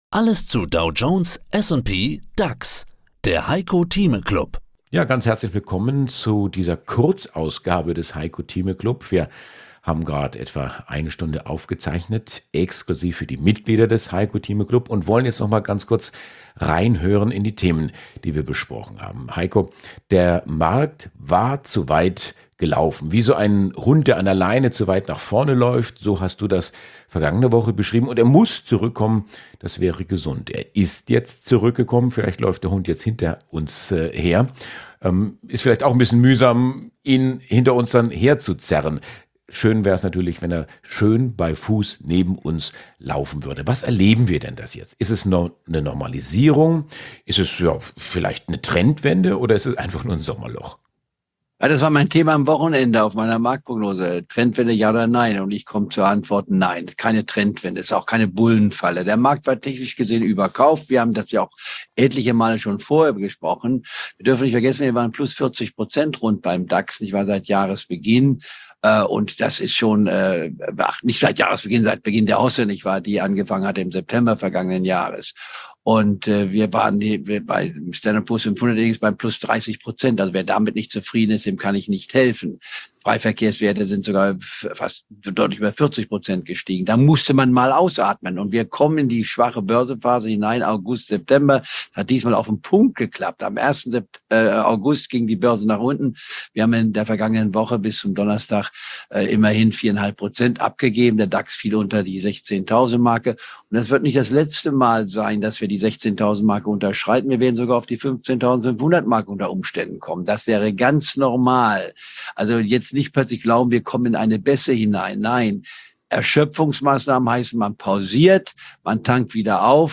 Im Heiko Thieme Club hören Sie ein mal wöchentlich ein exklusives Interview zum aktuellen Börsengeschehen, Einschätzung der Marktlage, Erklärungen wie die Börse funktioniert oder Analysen zu einzelnen Aktienwerten.